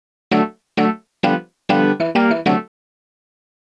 Swinging 60s 3 Organ-D.wav